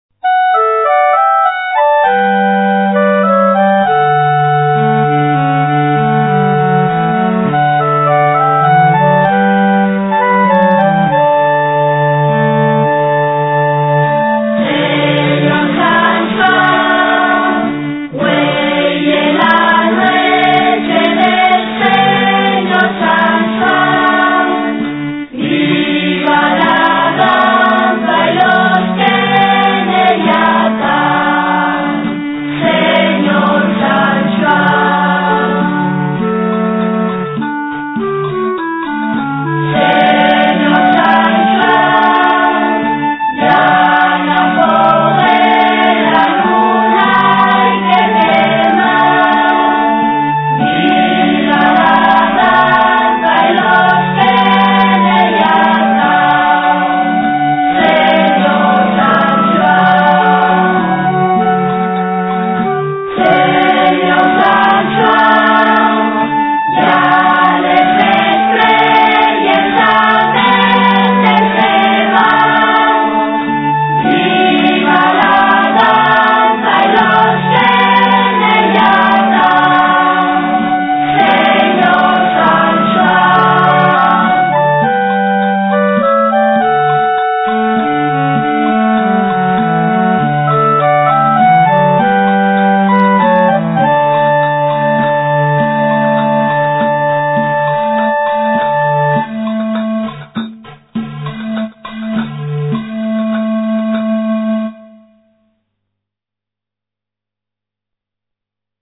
De xuru,que la lletra conozla fasta la vuesa güela porque son cancios de la tradición musical asturiana recoyíos munchos d'ellos del cancioneru musical d'Eduardo Martínez Torner.
Los que canten son los neños de los colexos públicos de Lieres y Sariegu y pa que-yos salga tan bien tienen qu'ensayar dos hores a la selmana